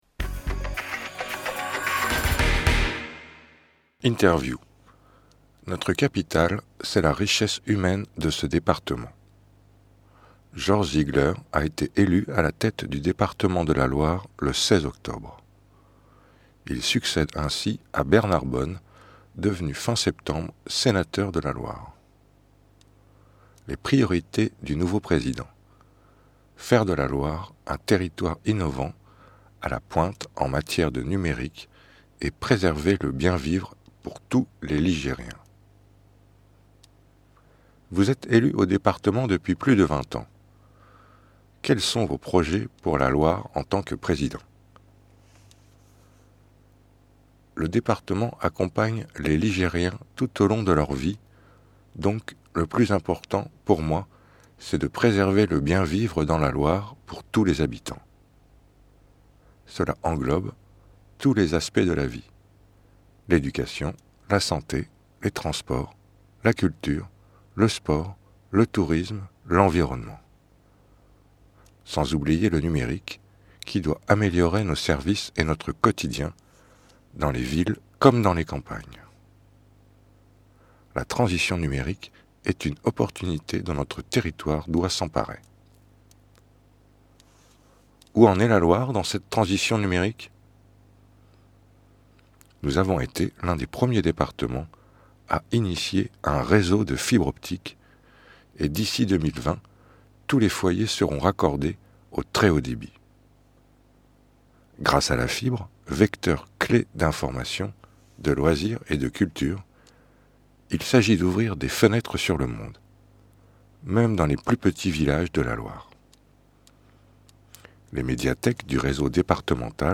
Interview de Georges Ziegler, nouveau président du Département de la Loire